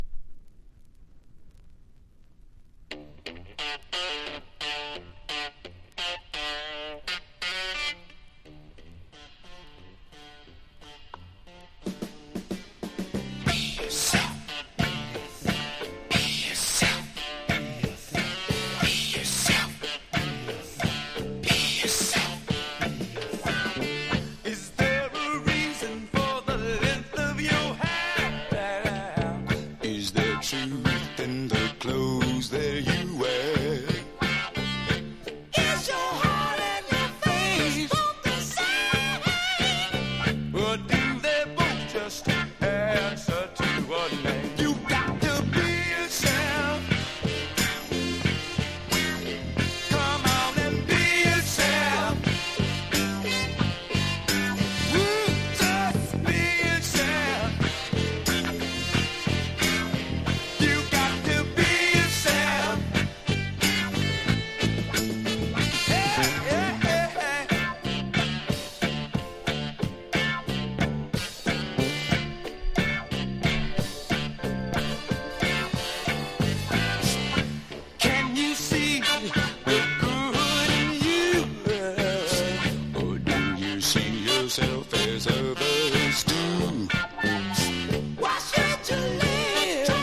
粘り気を含んだナイスファンクがズラリと並ぶ名作です!!：SOUL